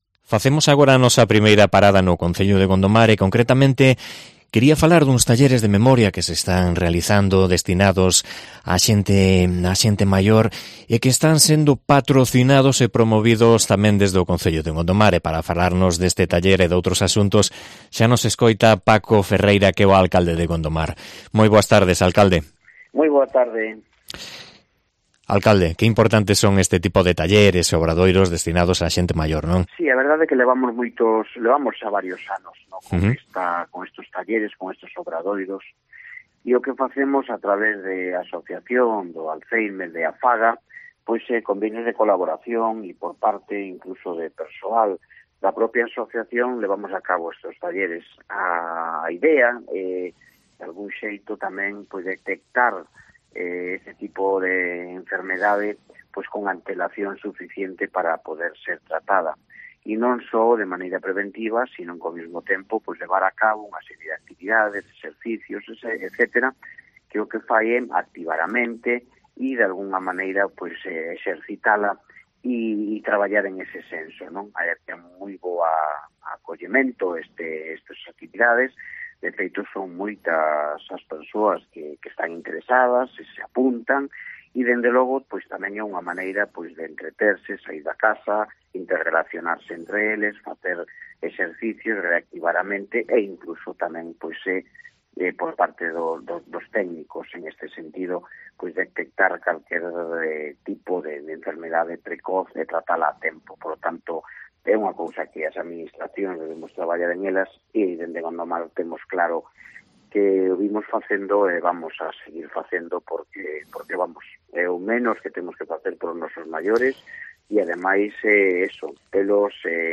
Entrevista a Paco Ferreira, alcalde de Gondomar